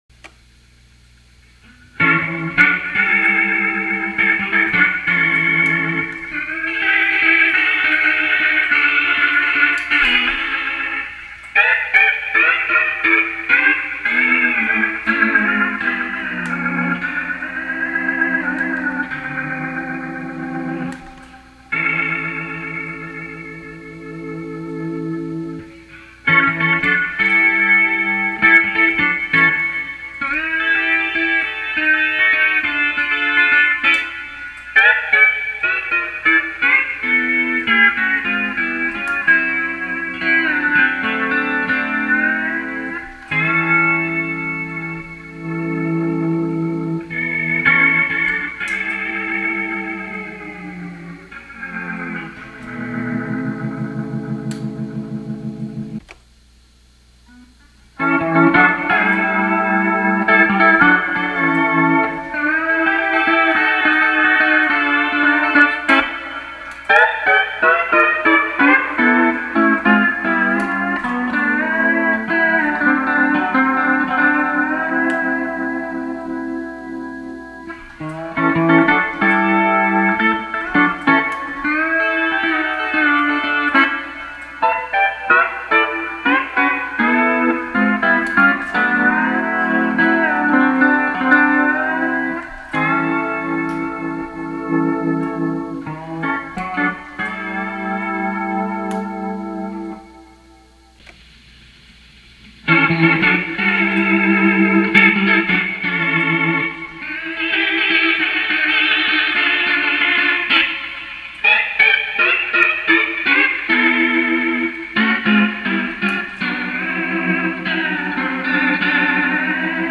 Click here for the Roto-Machine samples and visit my web page for an explanation of how they were recorded in stereo and what the settings were.